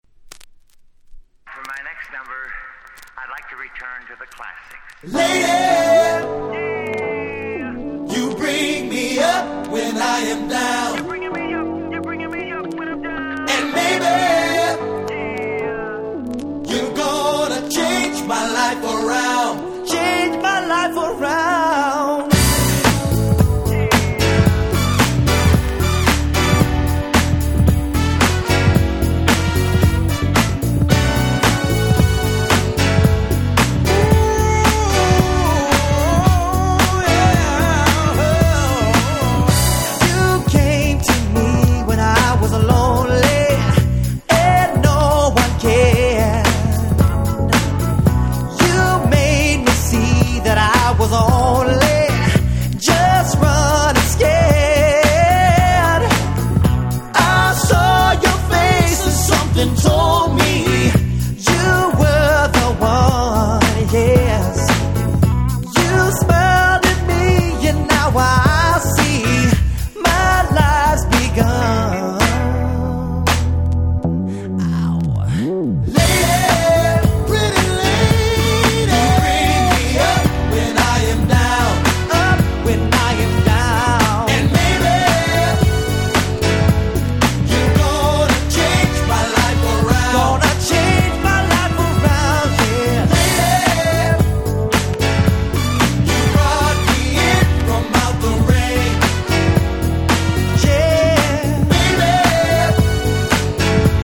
96' Nice Cover EU R&B !!